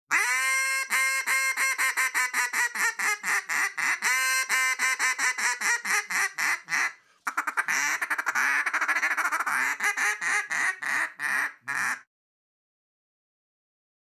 Sharp sounds.
The notes rise quickly, pierce cleanly. The bass rolls off gently.
single reed